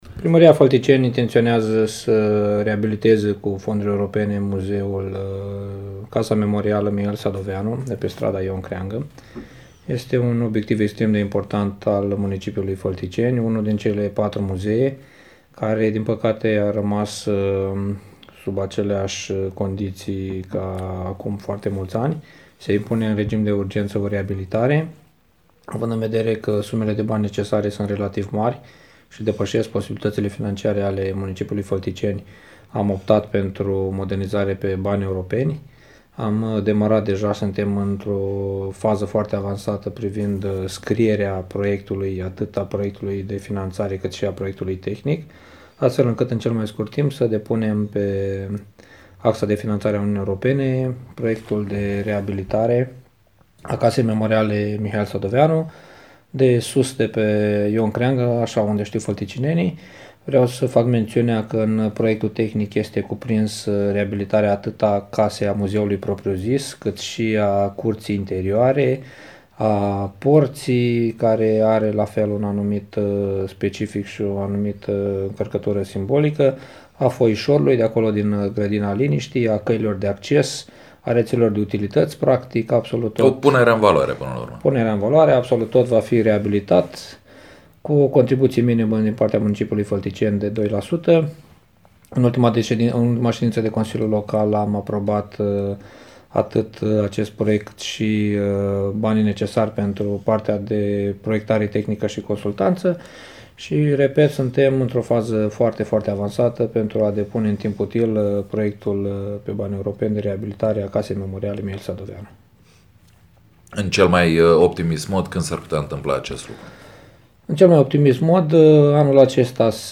Coman ne spune mai multe despre ce presupune reabilitarea Casei memoriale “Mihail Sadoveanu”: